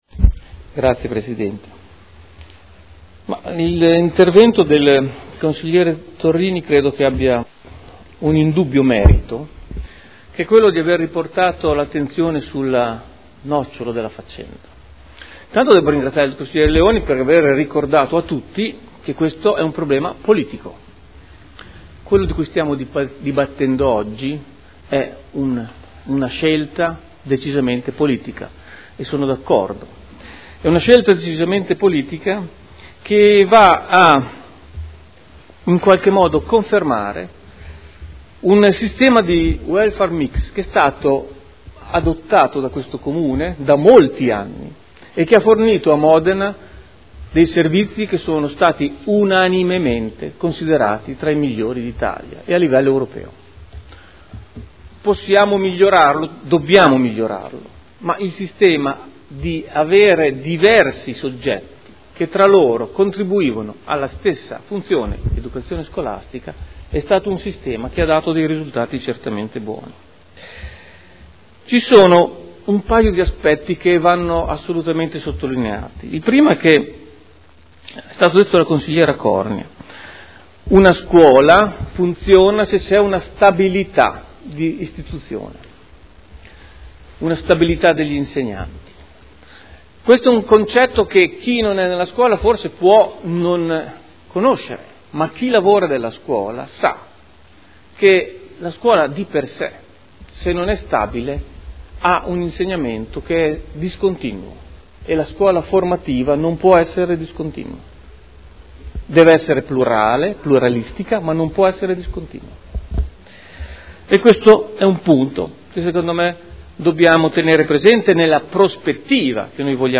Luigi Alberto Pini — Sito Audio Consiglio Comunale